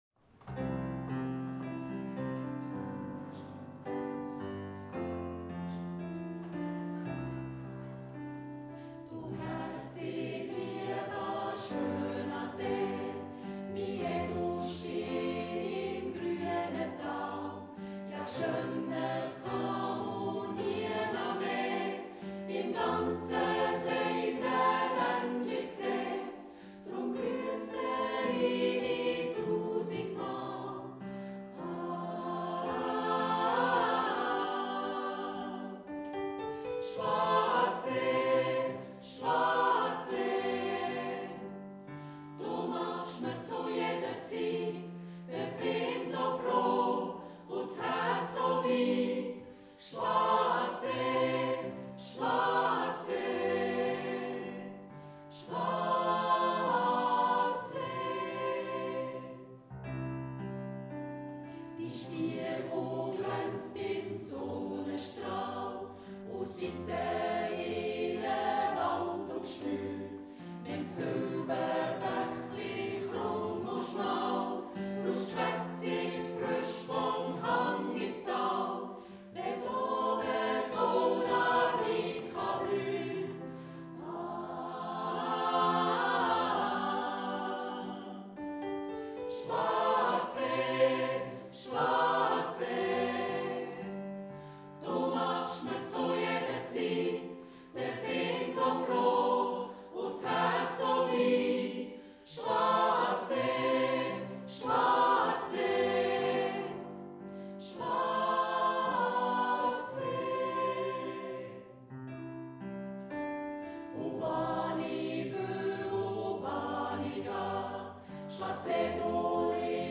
Live 2023